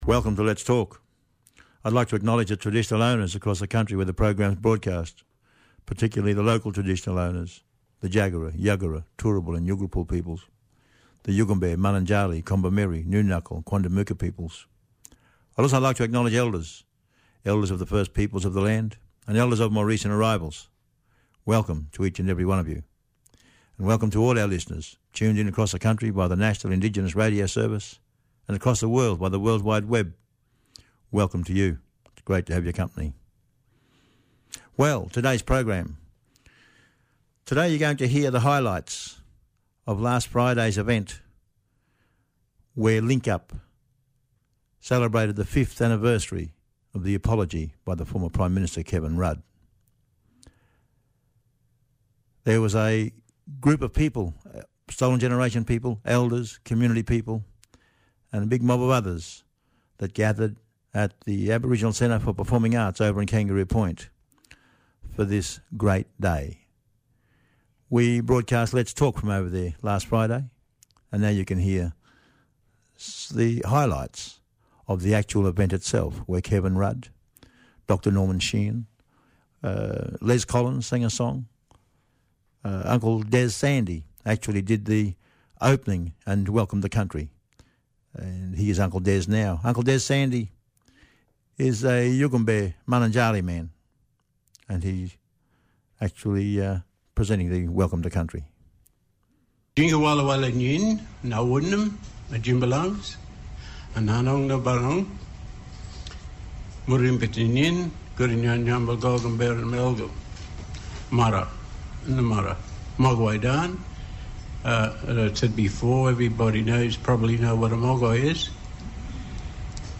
Highlights of Link-Up's 5th Anniversary Apology Event - Triple A
Includes a speech from Kevin Rudd